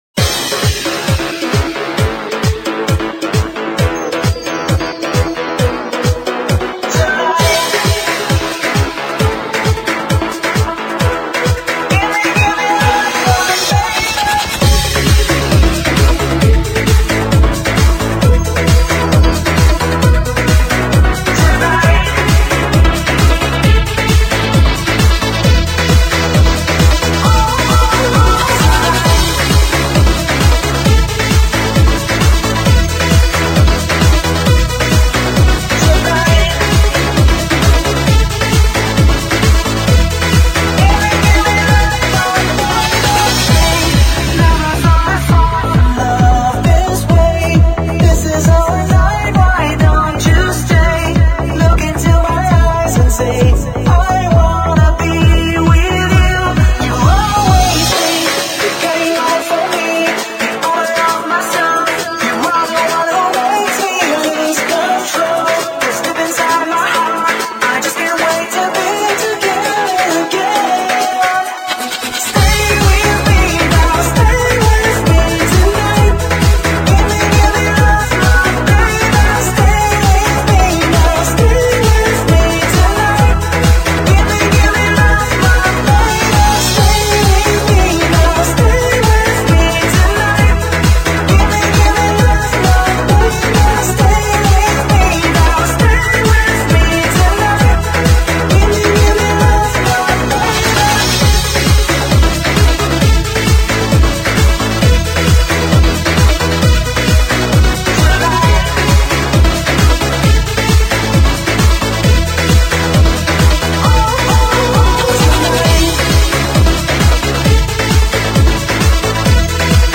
BPM133
Audio QualityMusic Cut